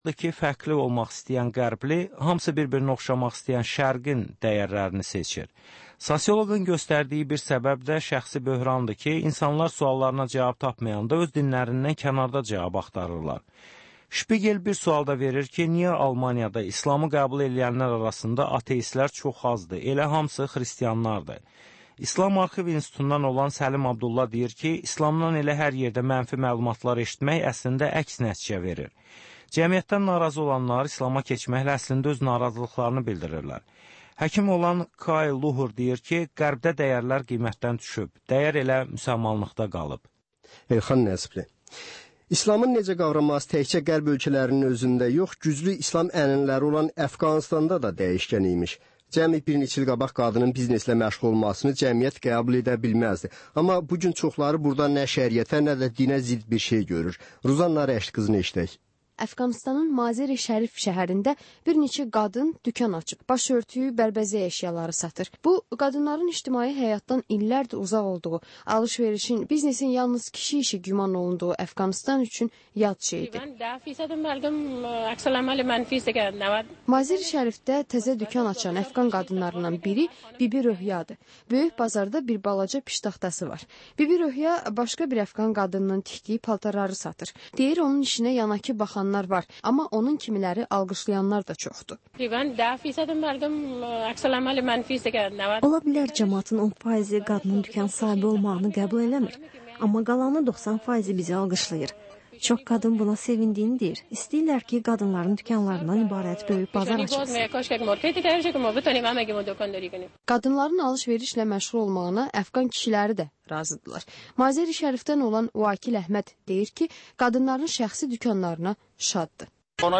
Həftənin aktual məsələsi barədə dəyirmi masa müzakirəsi